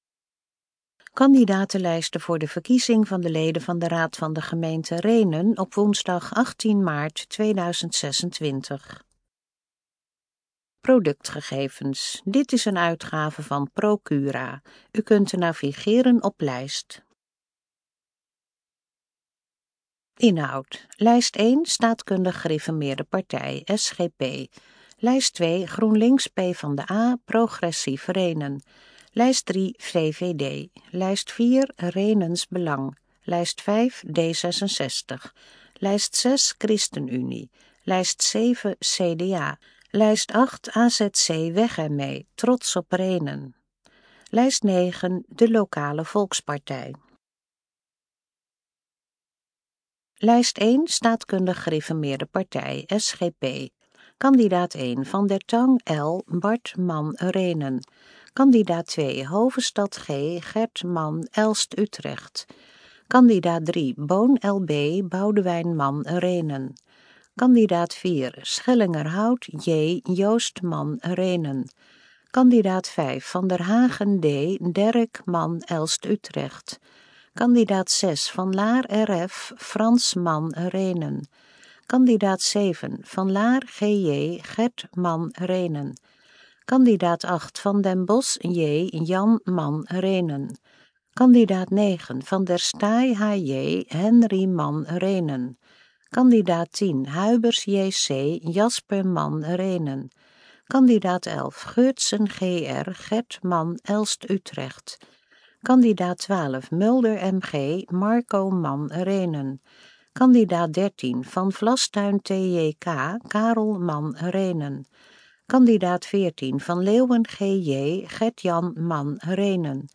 Kandidatenlijst_Gesproken_Vorm_GR26_Rhenen.mp3